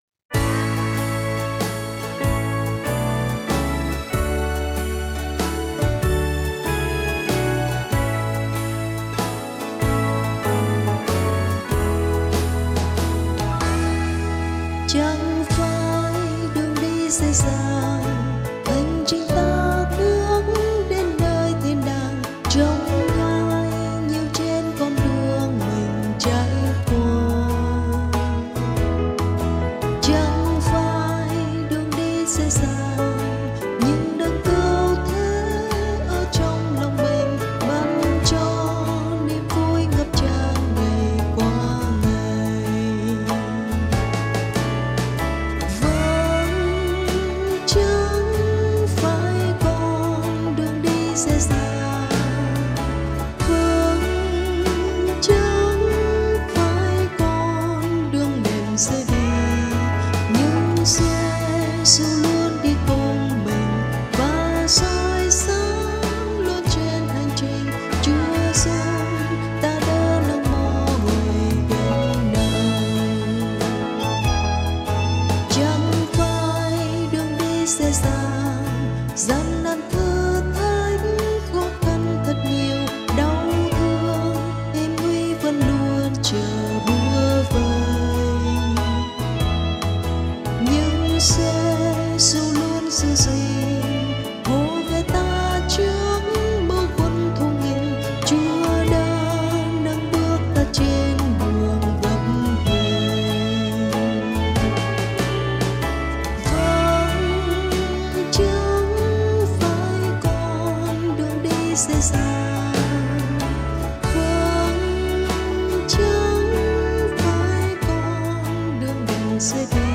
Nhạc Thánh